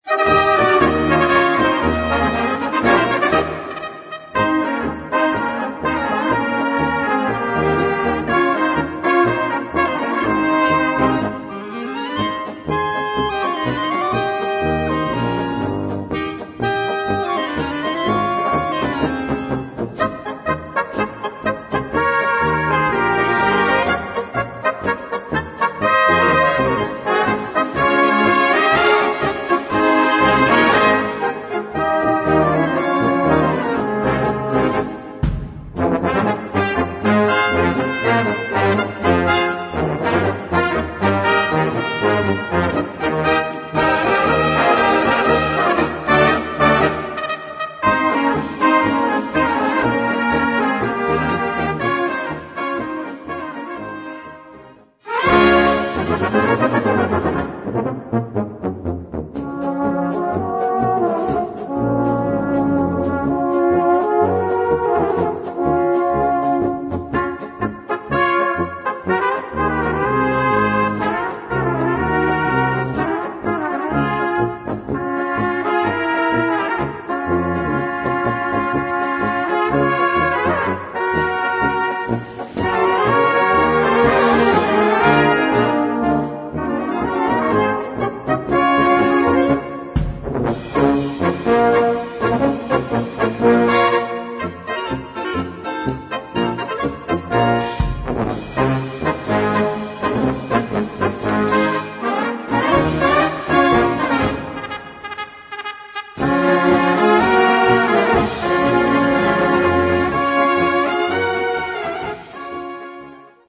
Gattung: Polka
Besetzung: Blasorchester
Eine technisch anspruchsvolle und farbenreiche Polka